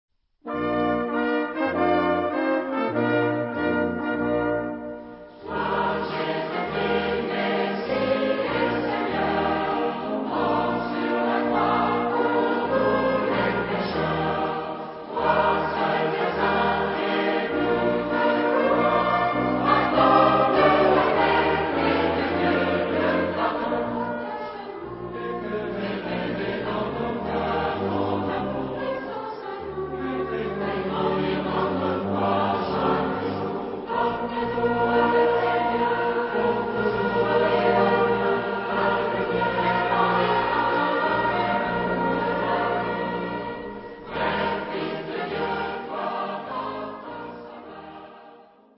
Genre-Style-Forme : Sacré ; Prière
Type de choeur : SATB  (4 voix mixtes )
Instruments : Orgue (1) ad lib
Tonalité : mi bémol majeur